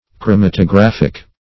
\chro*mat`o*graph"ic\